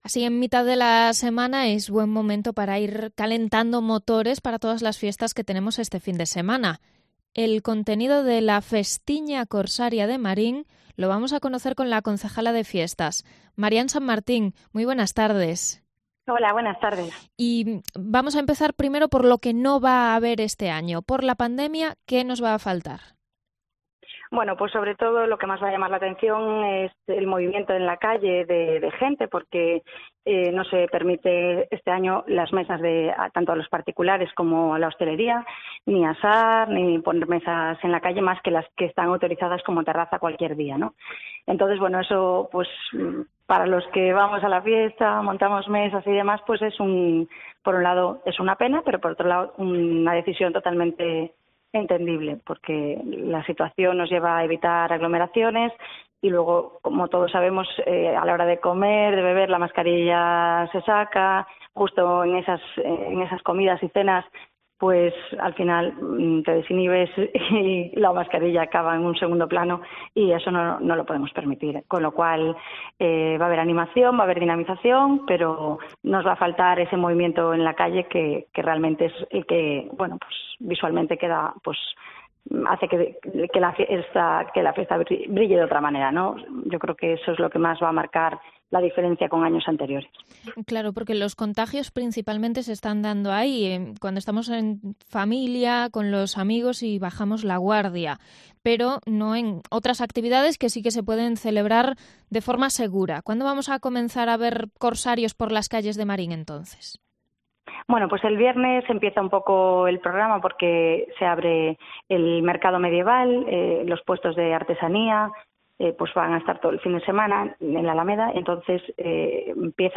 Entrevista a la concejala de fiestas de Marín antes de la Festiña Corsaria